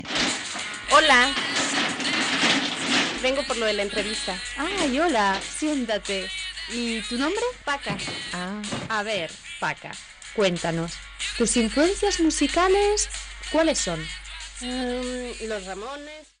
Fragment d'emissió sobre preferècncies musicals
Extret del programa "El ojo crítico" de Radio Nacional de España emès a l'octubre del 2007.